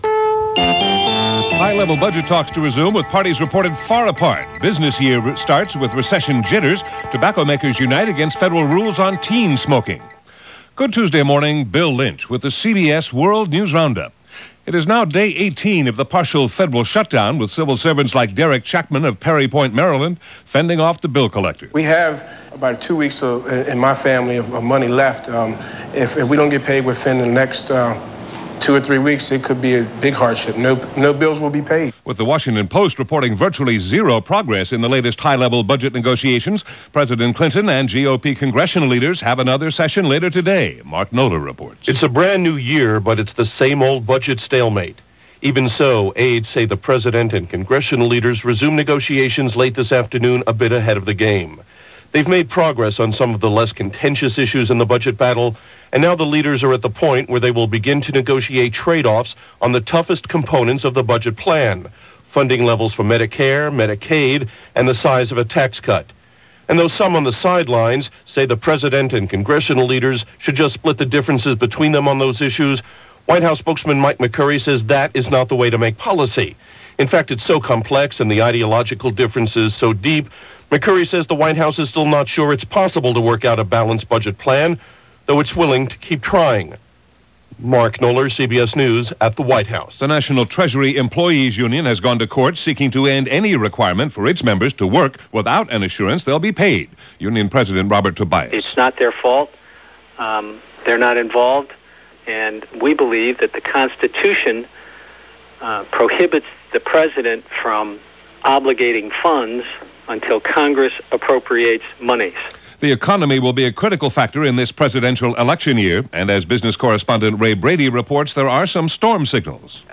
– January 2, 1996 – CBS World News Roundup – Gordon Skene Sound Collection –